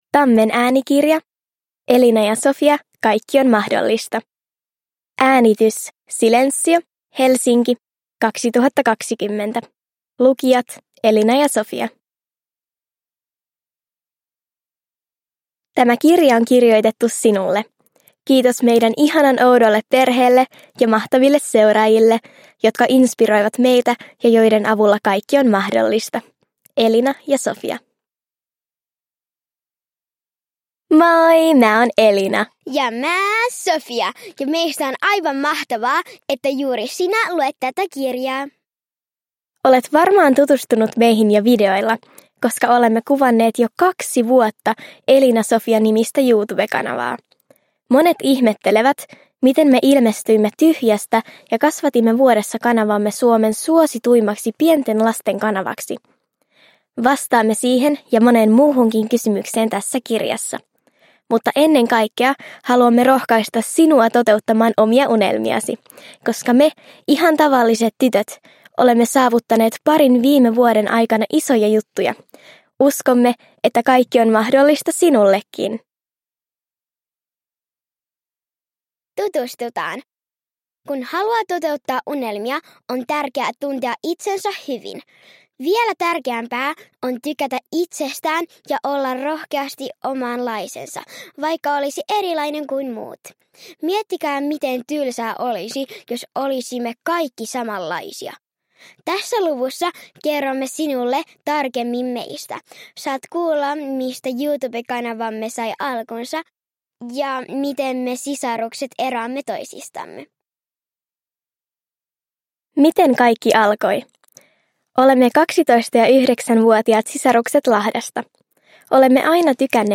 – Ljudbok – Laddas ner